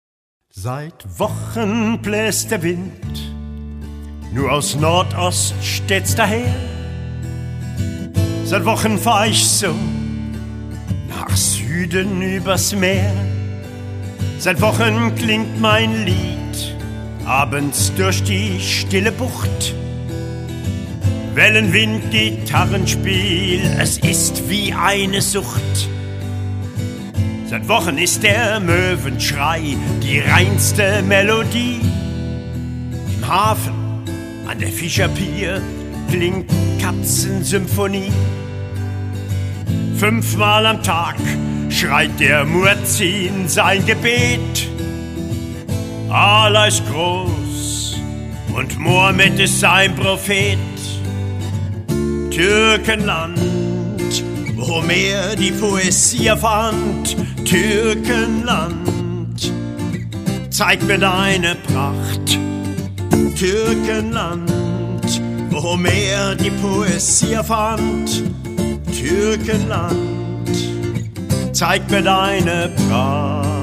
Authentische Lieder eines Abenteurers und Straßenmusikers.